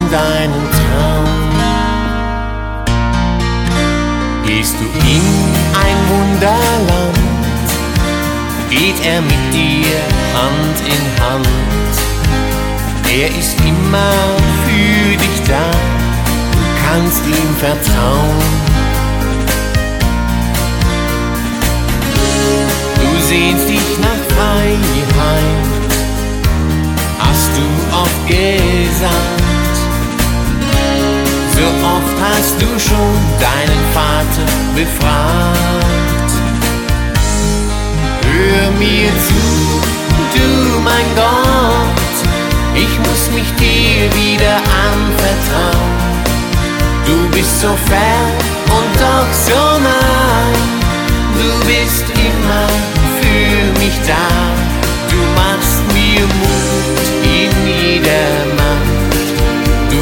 • Sachgebiet: Schlager
Liedermacher, Worship 0,99 €